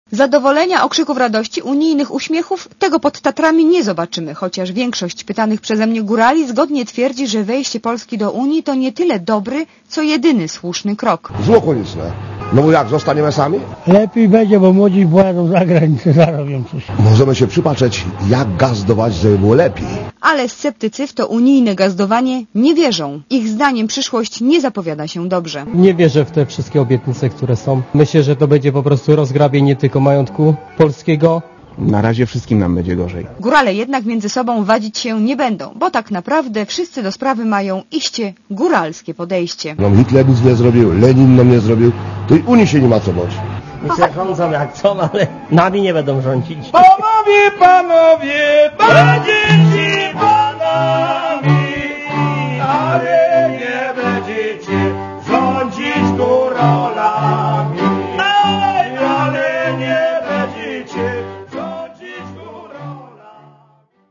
Górale
gorale.mp3